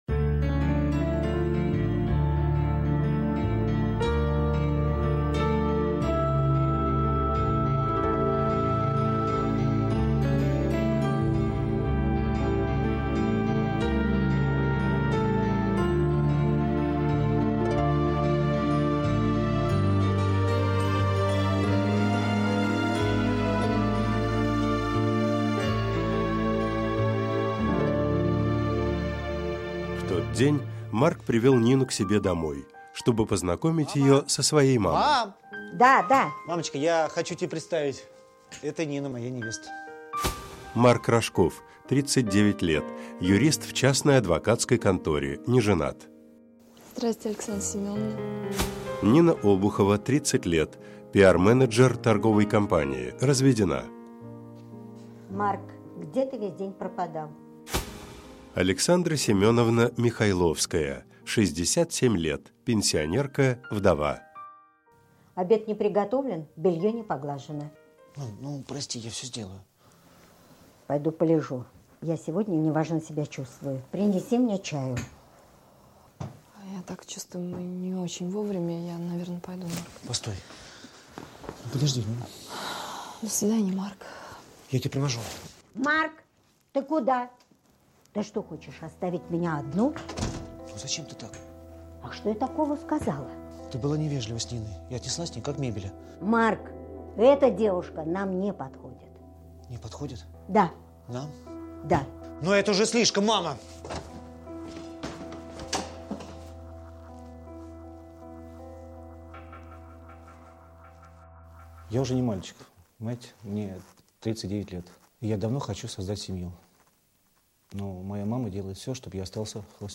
Аудиокнига Мама, это моя невеста | Библиотека аудиокниг